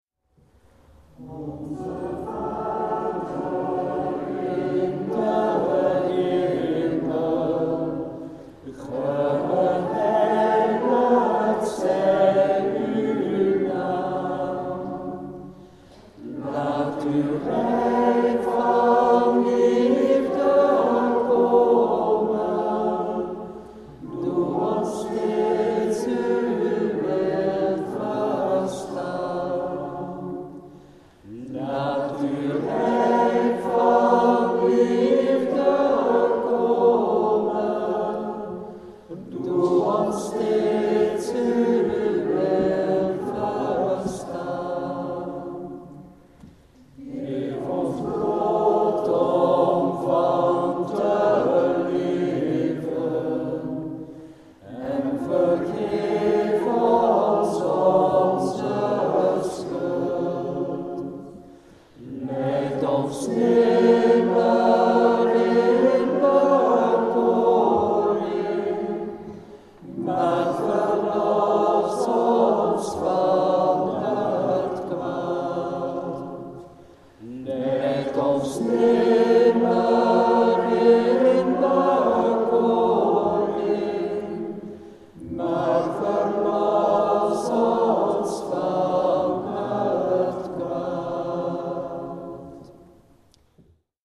De eucharistieviering 's morgens